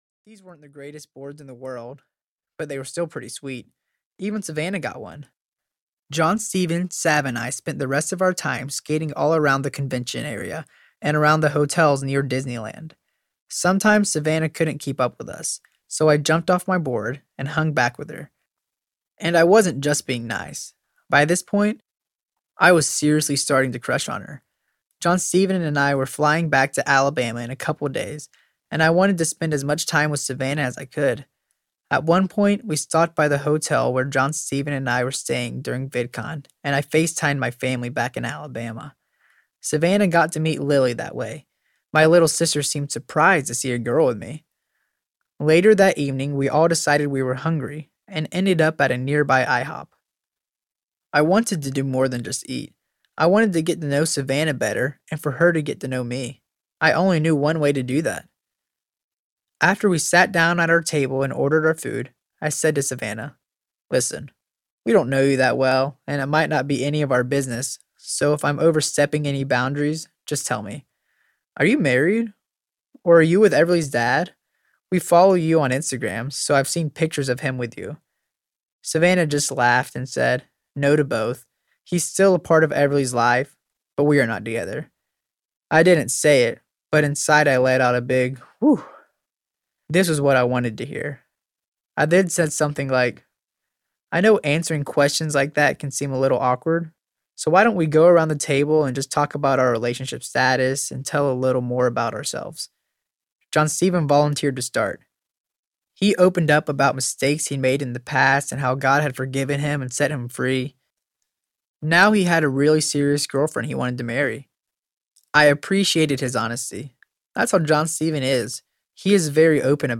Cole and Sav Audiobook
6.33 Hrs. – Unabridged